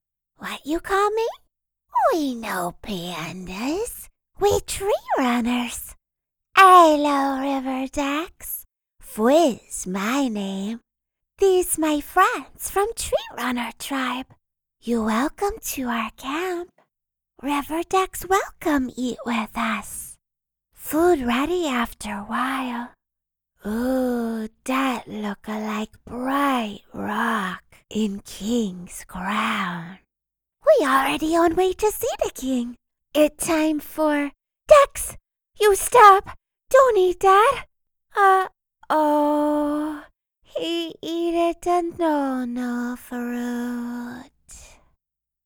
动画角色-法师